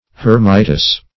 Hermitess \Her"mit*ess\, n. A female hermit.